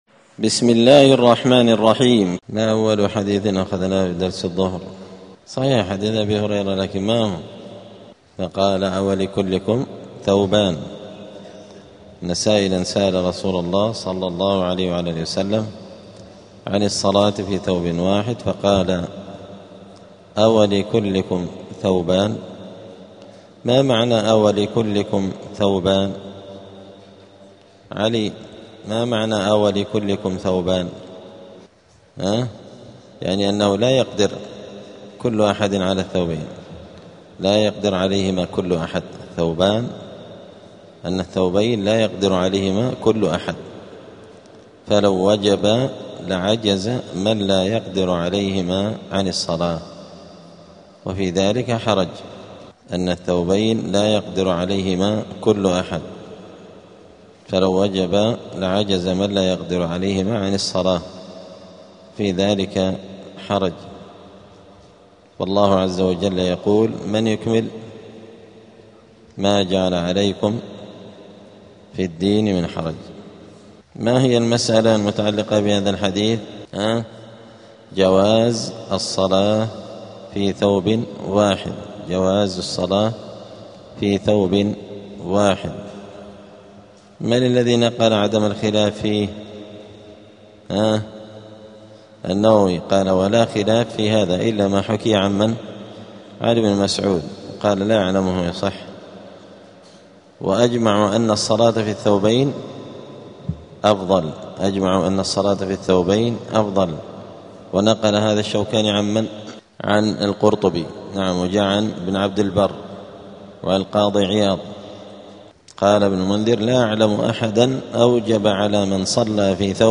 دار الحديث السلفية بمسجد الفرقان قشن المهرة اليمن
الدروس الأسبوعية